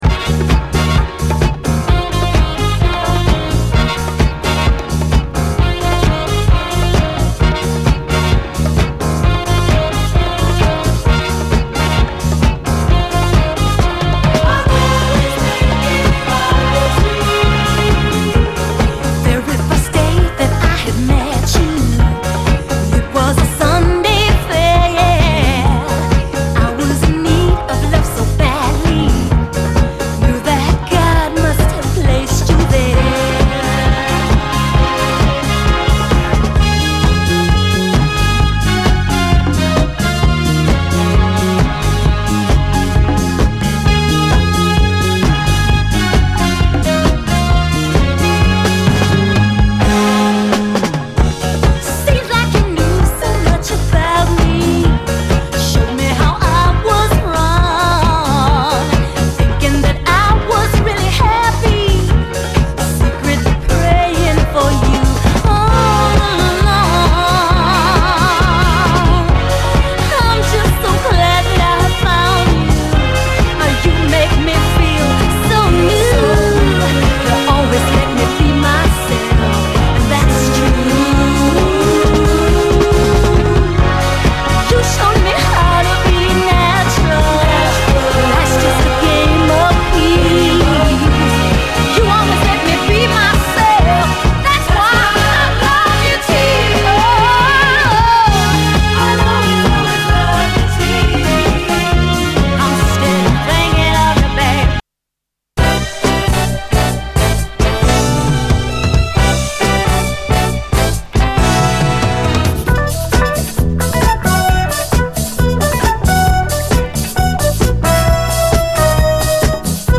SOUL, 70's～ SOUL, DISCO